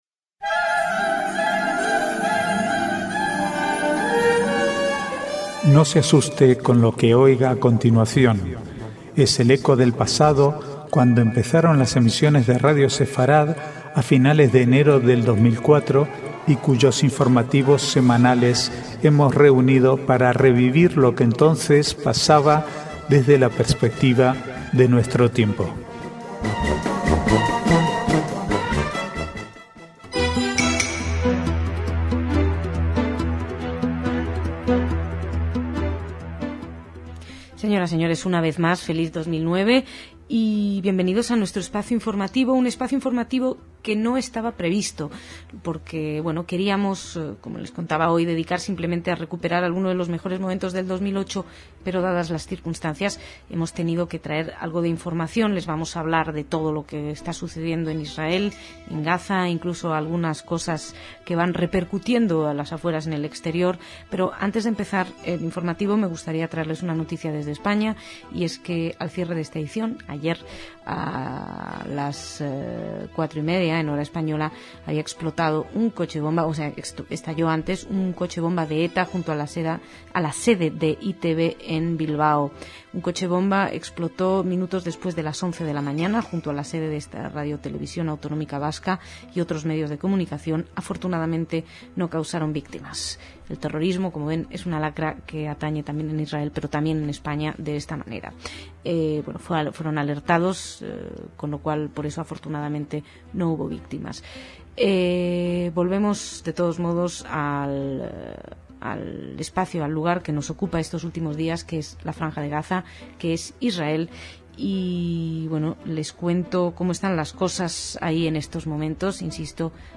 Archivo de noticias del 1 al 7/1/2009